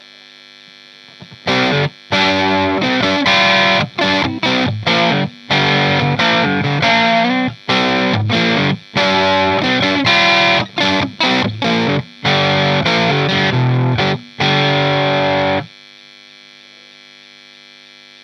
guitare (strat tokai ou melody maker ou westone thunder) -> ampli -> cab 2x12 greenback -> micro shure PG57 -> preamp micro -> numérisation (M audio 1010lt)
un petit bémol pour la prise de son sur le coté "pétillant" des samples en satu, en direct c'est pas "pétillant" de l'aigu.
encore un petit coup de strat
a la westone avec ses gros HB qui tachent